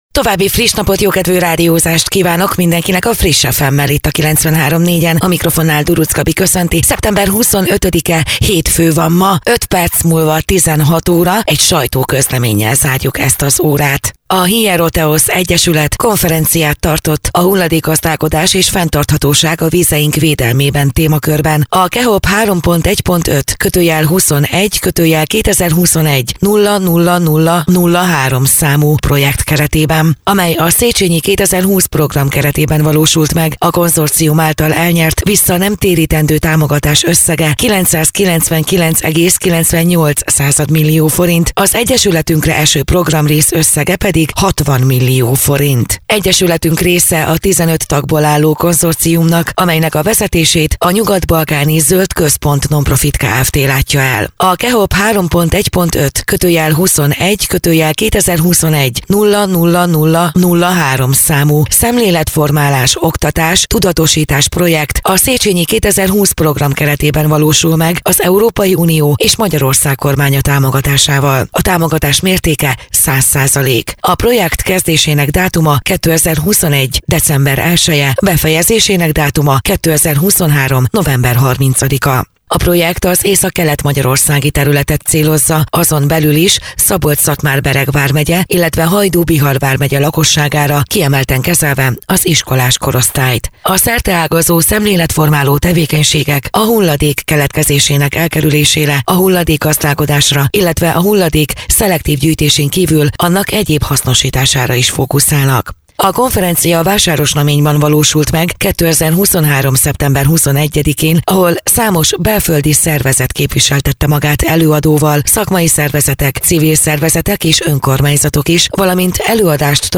Rádió
2023.szeptember 21.: Vásárosnamény - Hulladékgazdálkodás és fenntarthatóság a vizeink védelmében - sajtóközlemény